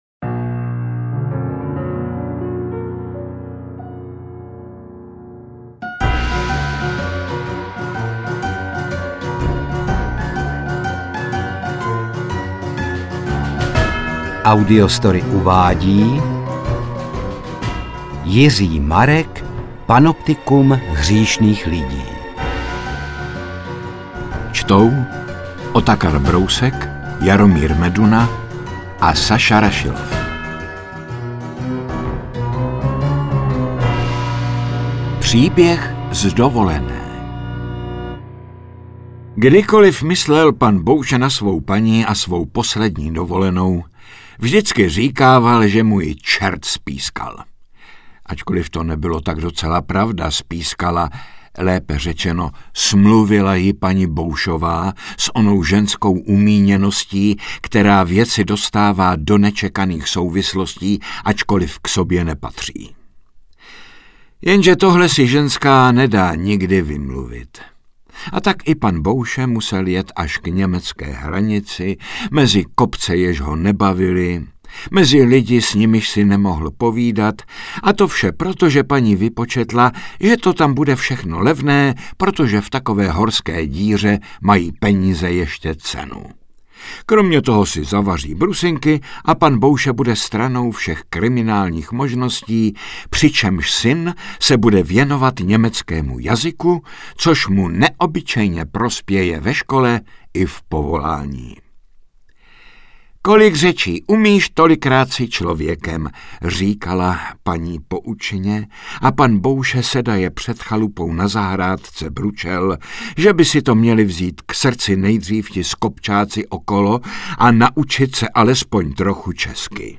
• AudioKniha ke stažení Panoptikum hříšných lidí
Interpreti:  Otakar Brousek, Jaromír Meduna, Saša Rašilov